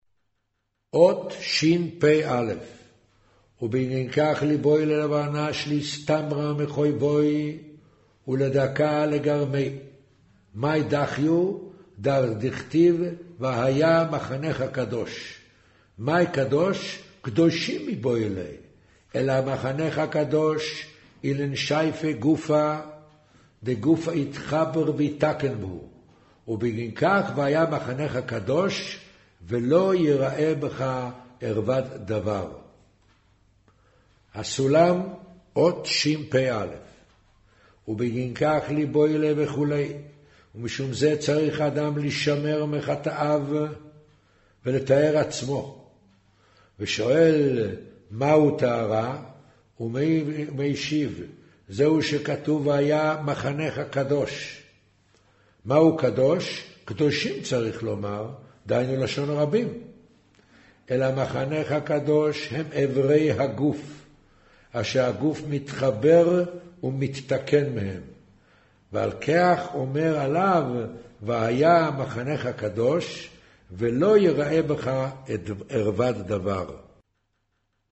קריינות זהר, פרשת נח, מאמר ויאמר ה' הן עם אחד